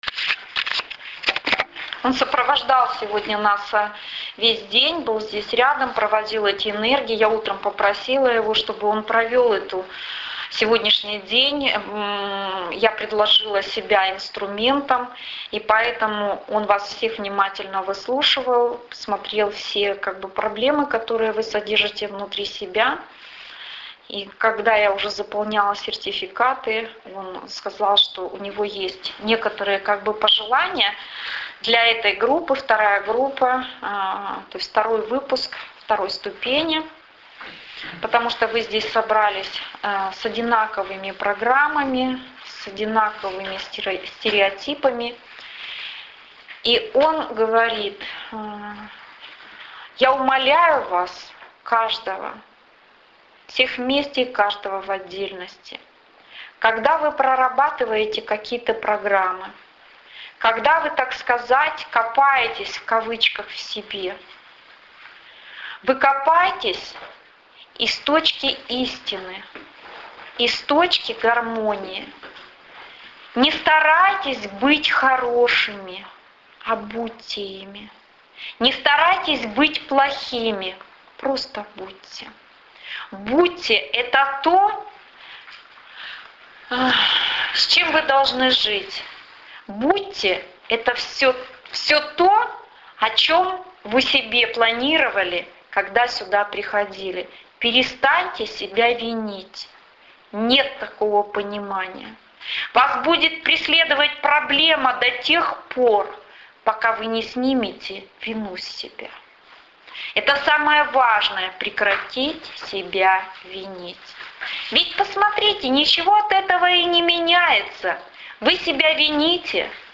Это послание пришло от Мерлина в конце занятий 2 ступени Радужной Медицины Ангелов.